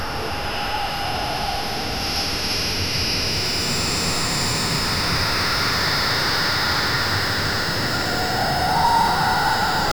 time-freezes-arrows-stop--iv2cresw.wav